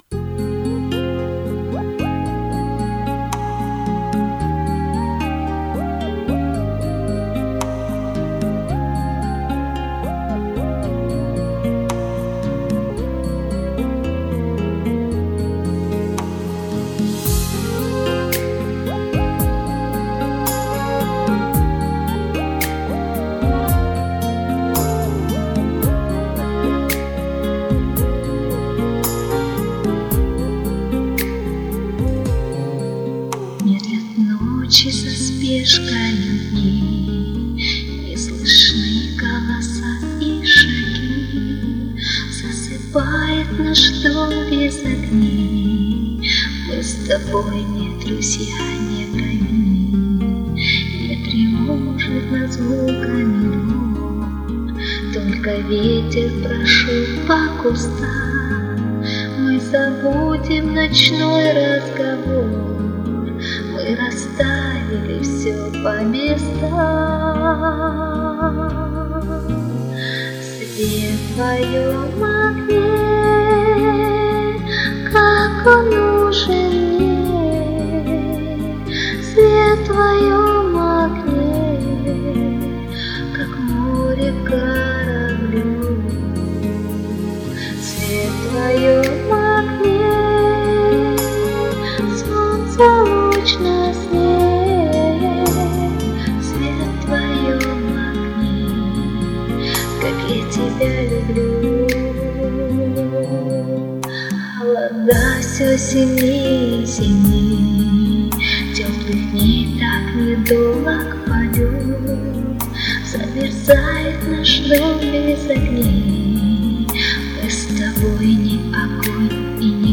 девчонки, да, у обеих качество звука страдает.